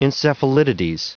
Prononciation du mot encephalitides en anglais (fichier audio)
Prononciation du mot : encephalitides